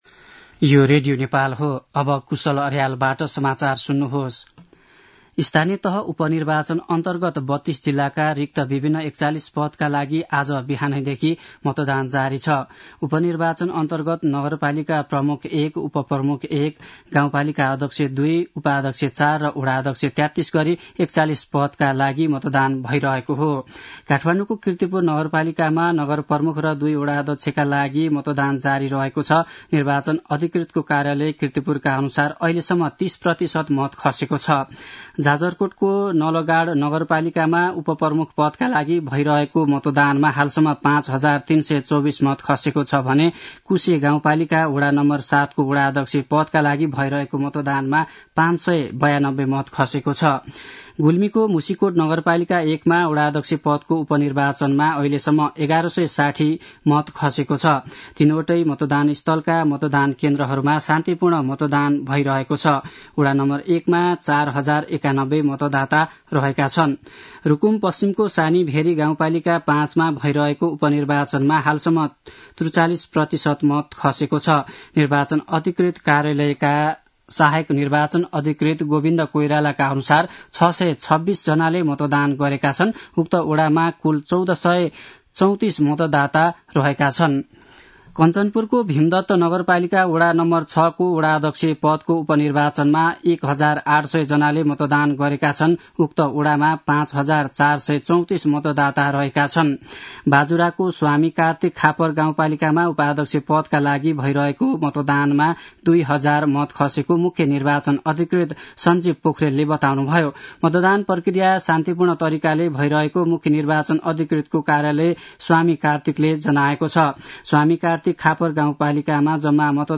दिउँसो १ बजेको नेपाली समाचार : १७ मंसिर , २०८१
1-pm-nepali-news-.mp3